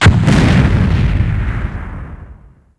bazooka.wav